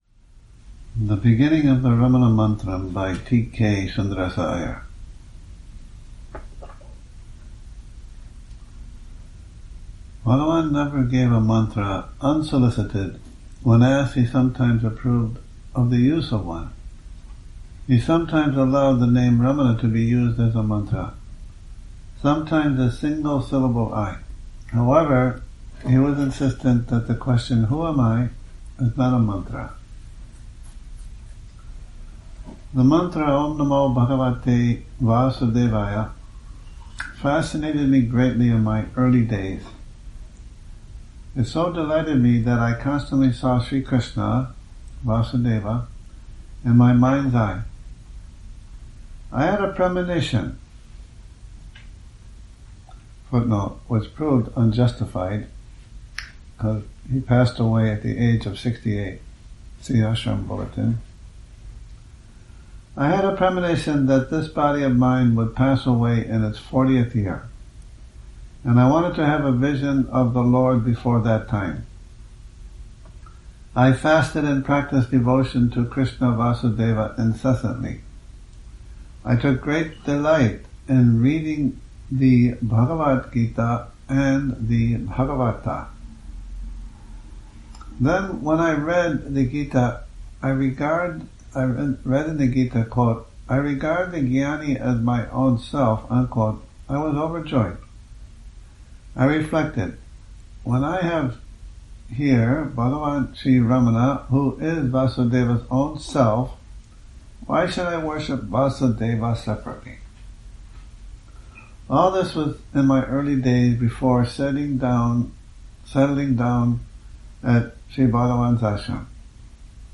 Morning Reading, 11 Nov 2019